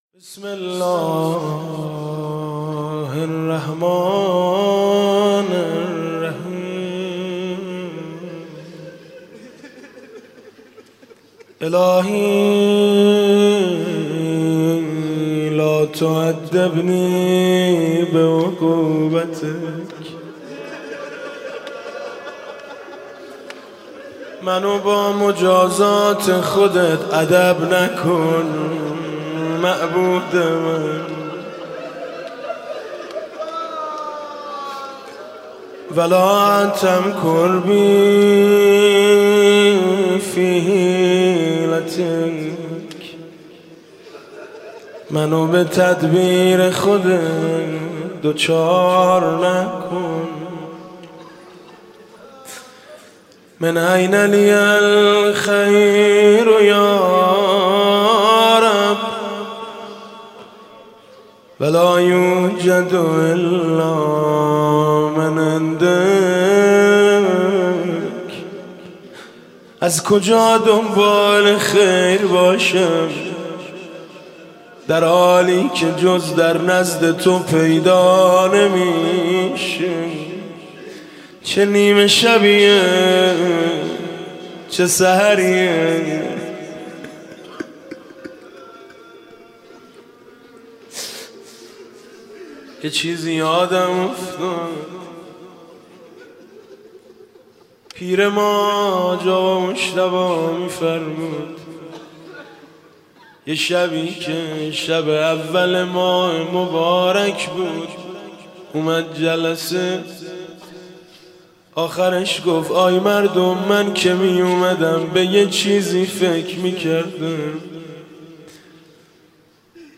شب اول رمضان 96 - هیئت شهدای گمنام - مناجات و روضه - مرغی که زمین خورد پرش را نزنید